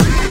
Track 14 - Hit FX OS 02.wav